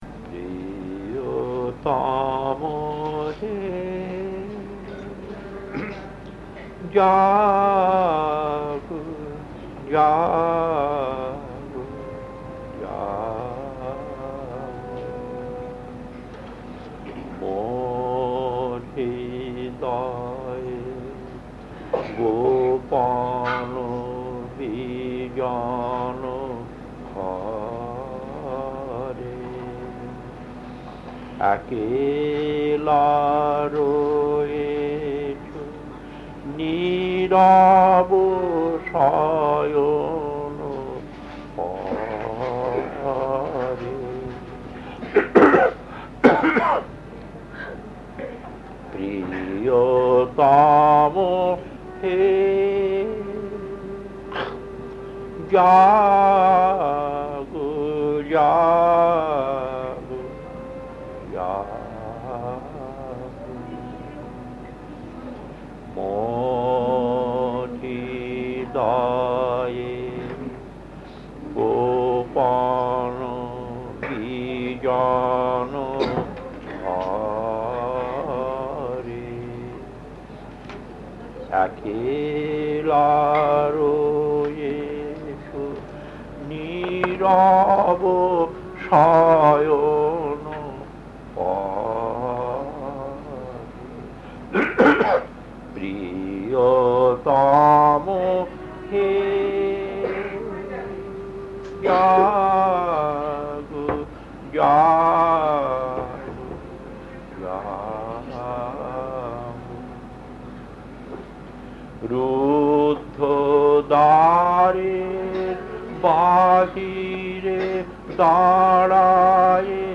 Kirtan A3-1 Puri 1981 1.